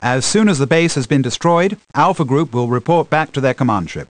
―An Imperial briefing officer, in reference to Control — (audio)
BriefingOfficer-AlphaCommandShip-TIEFighter.ogg